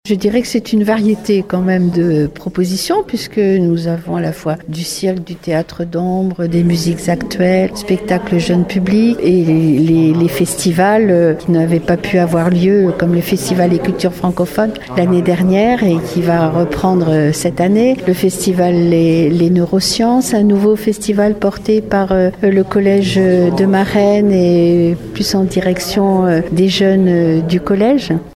La Ville a présenté ce mardi le programme d’animations du premier semestre de cette nouvelle année qui commence. Un programme chargé que nous détaille la maire Claude Balloteau :